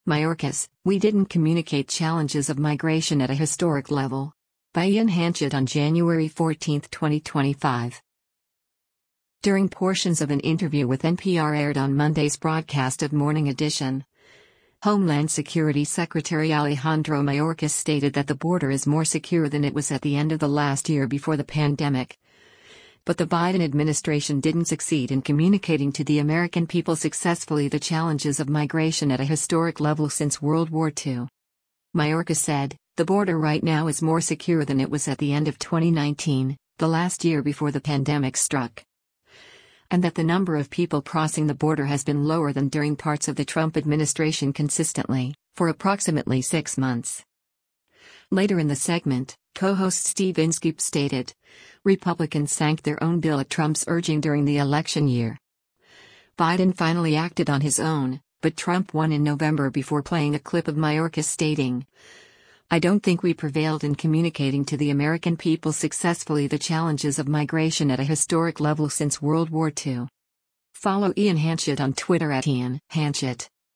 During portions of an interview with NPR aired on Monday’s broadcast of “Morning Edition,” Homeland Security Secretary Alejandro Mayorkas stated that the border is more secure than it was at the end of the last year before the pandemic, but the Biden administration didn’t succeed “in communicating to the American people successfully the challenges of migration at a historic level since World War II.”